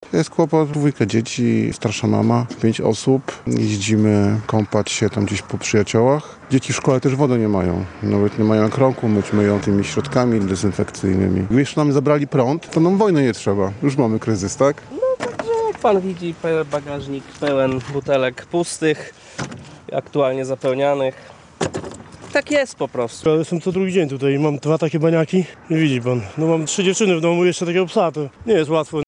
Z mieszkańcami Kosakowa rozmawiał nasz reporter.